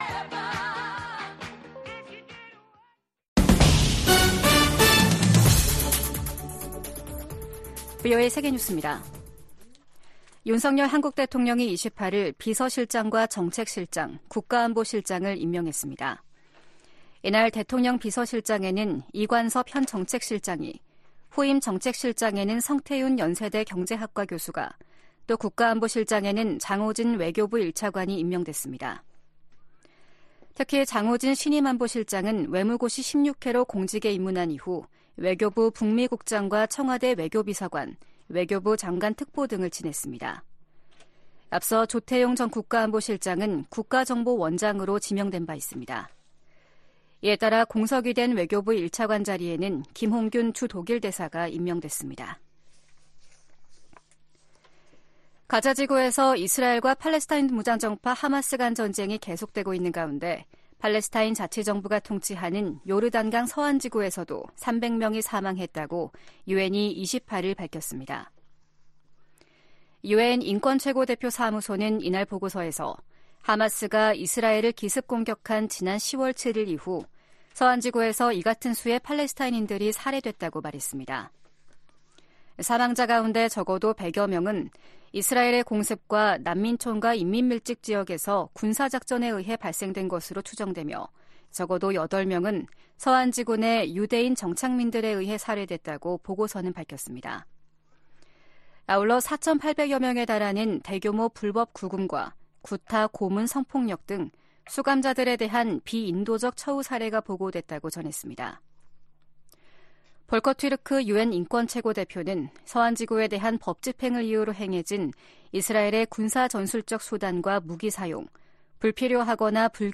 VOA 한국어 아침 뉴스 프로그램 '워싱턴 뉴스 광장’ 2023년 12월 29일 방송입니다. 김정은 북한 국무위원장이 당 전원회의서 ‘전쟁 준비에 박차’를 가하는 전투과업을 제시했습니다. 미국 국무부가 북한의 중요 정치행사에 등장한 ‘벤츠 행렬’에 대해 대북 제재의 ‘운송수단 반입 금지’ 의무를 상기시켰습니다. 전 주한미군사령관들이 신년 메시지를 통해 미한 연합훈련과 가치 동맹의 확대를 주문했습니다.